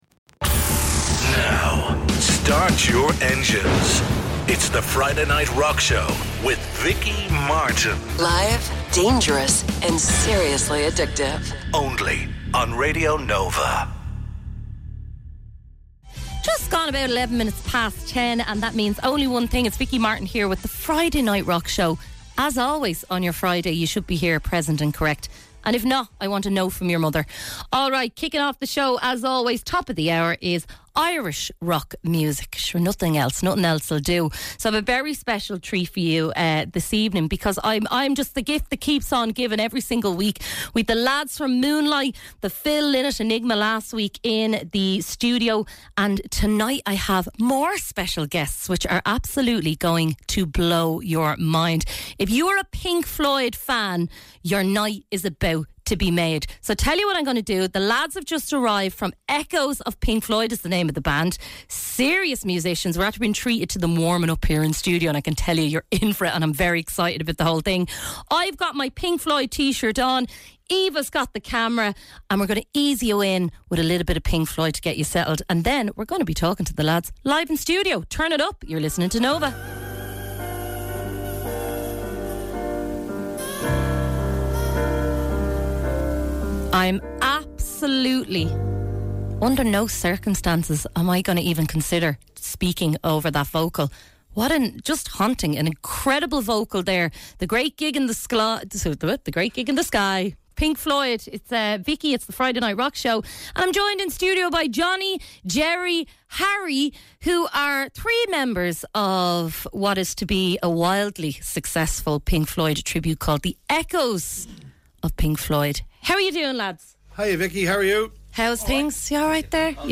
1 Prof. Dame Sue Black, Forensic Scientist: Classics & Careers 32:06 Play Pause 1d ago 32:06 Play Pause Play later Play later Lists Like Liked 32:06 We have a bonus episode for you... what better way to close out Season 2 than with an interview with one of the most fascinating people we've ever met - Baroness Black. Sue, as she prefers, is a trailblazing scientist and a champion for education and, indeed classical subjects.